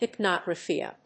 音節hyp・no・thérapy 発音記号・読み方
/hìpnoʊ‐(米国英語)/